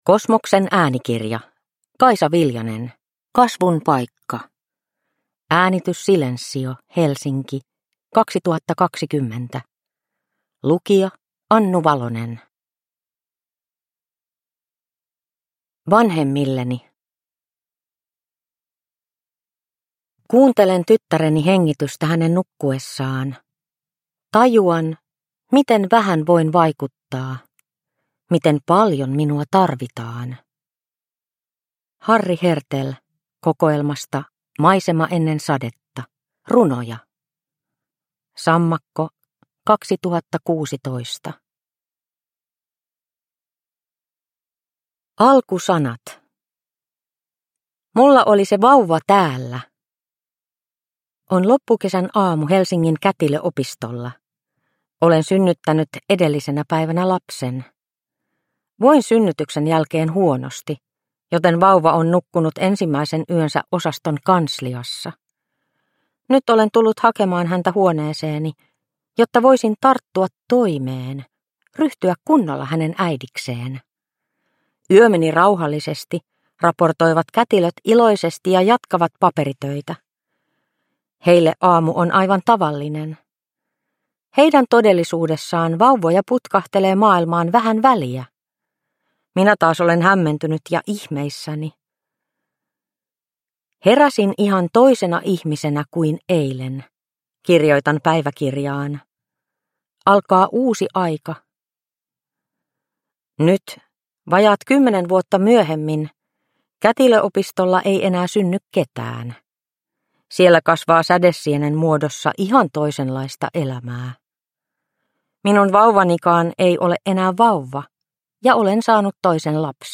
Kasvun paikka – Ljudbok – Laddas ner